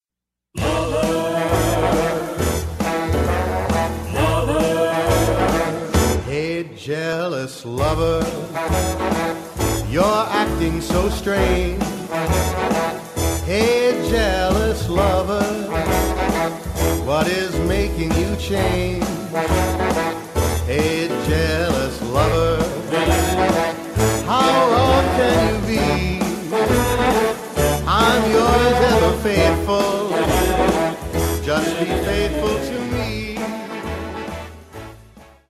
Recueil pour Baryton/basse - Basses